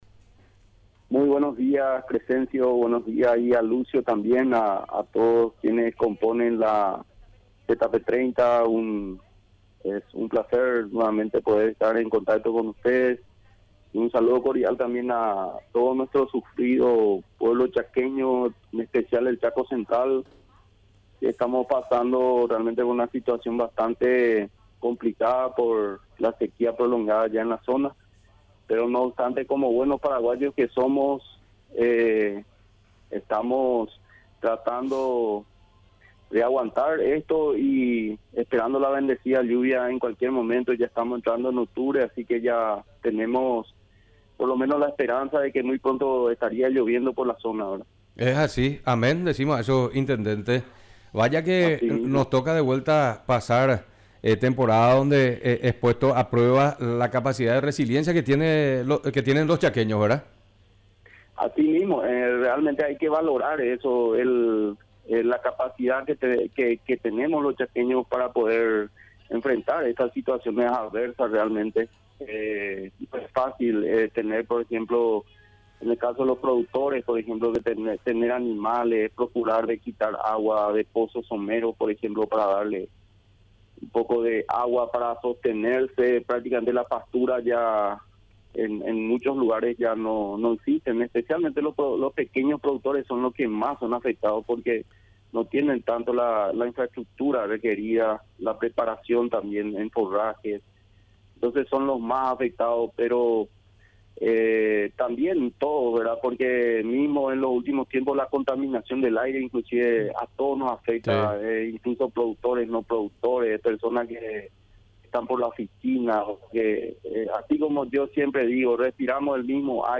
Entrevistas / Matinal 610 Perforación de pozos someros Oct 04 2024 | 00:26:07 1x 00:00 / 00:26:07 Subscribe Share RSS Feed Share Link Embed